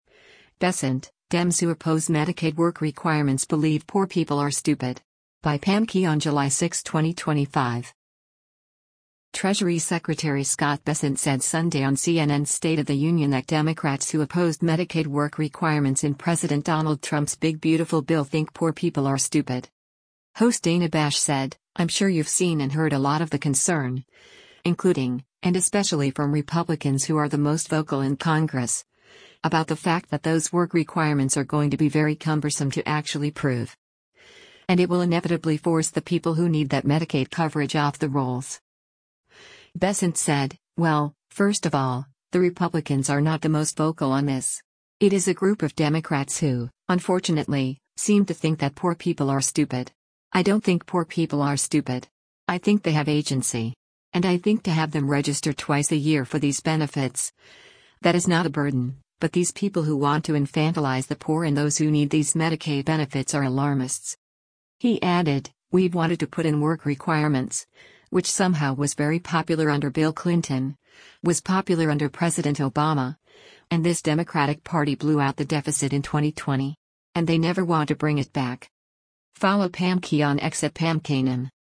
Treasury Secretary Scott Bessent said Sunday on CNN’s “State of the Union” that Democrats who opposed Medicaid work requirements in President Donald Trump’s “Big Beautiful Bill” think “poor people are stupid.”